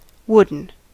Ääntäminen
US Tuntematon aksentti: IPA : /ˈwʊdən/